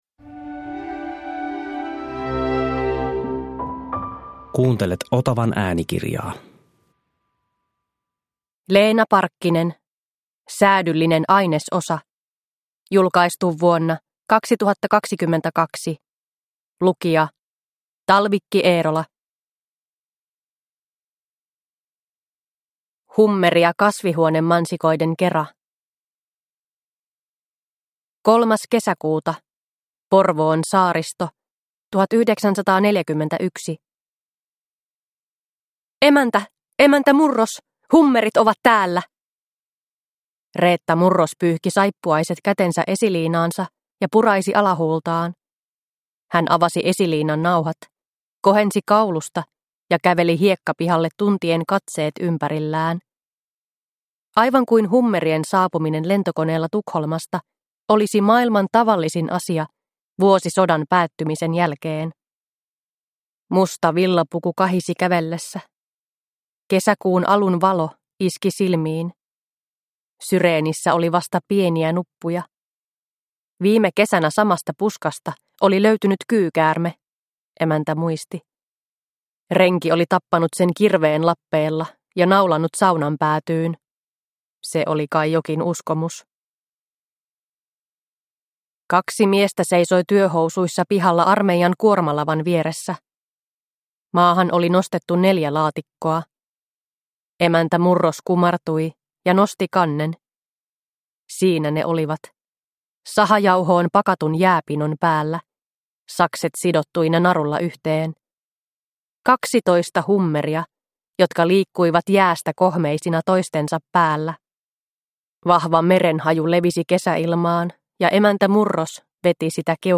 Säädyllinen ainesosa – Ljudbok – Laddas ner